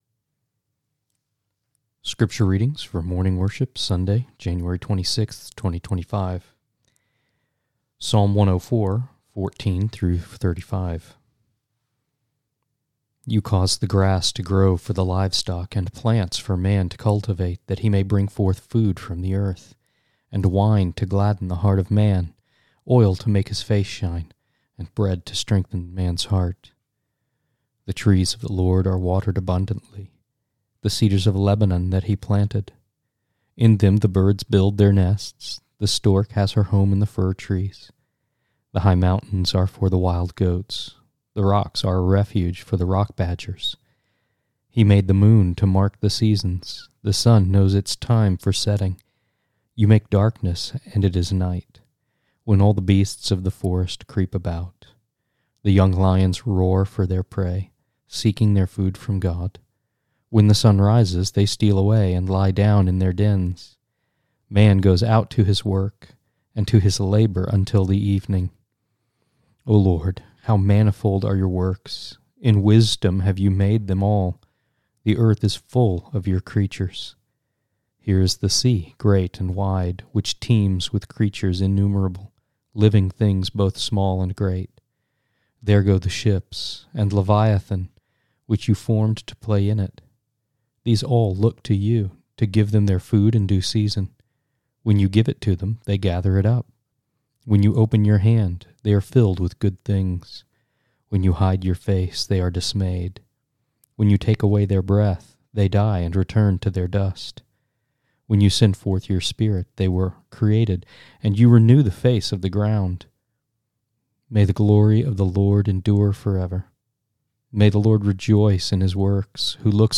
1 Scripture Readings, Morning Worship | Sunday, January 26, 2025